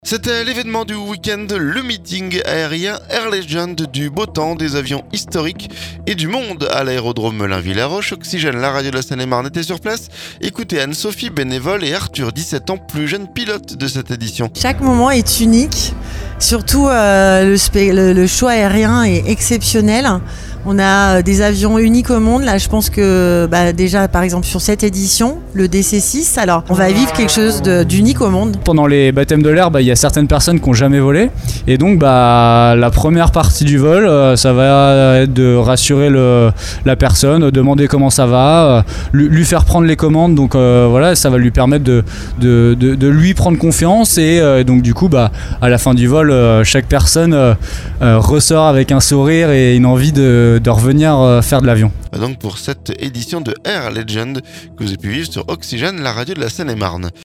C'était l'événement du week-end : le meeting aérien Air Legend... Du beau temps, des avions historiques et du monde à l'aérodrome Melun-Villaroche. Oxygène, la radio de la Seine-et-Marne était sur place.